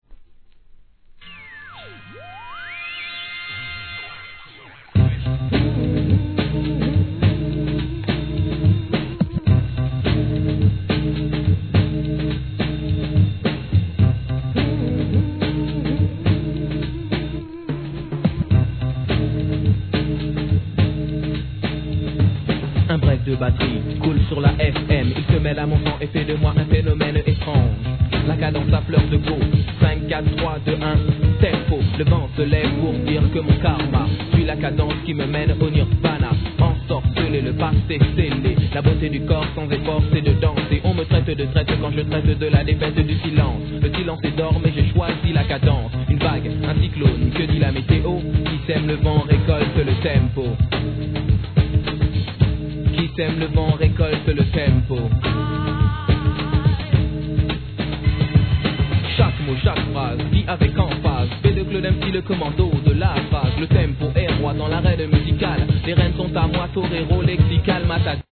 HIP HOP/R&B
得意のJAZZYサウンドを乗りこなす洒落た1枚!!